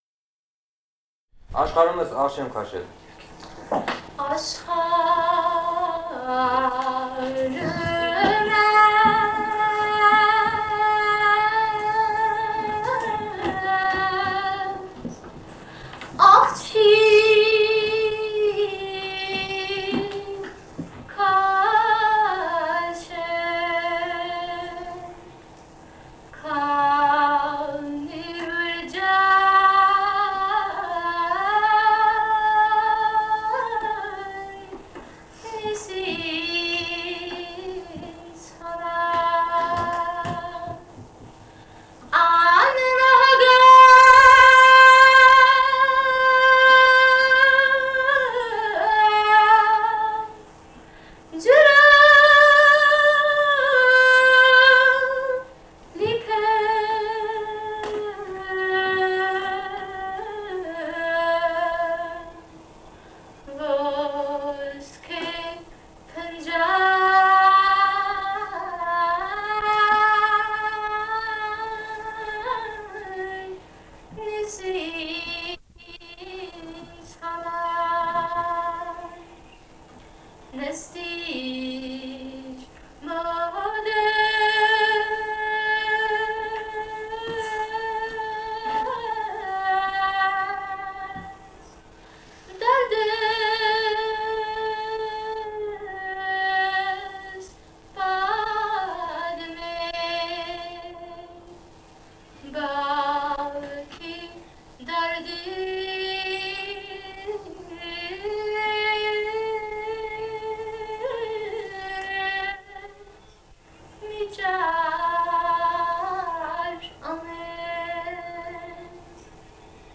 полевые материалы
Грузия, с. Дилифи, Ниноцминдский муниципалитет, 1971 г. И1310-21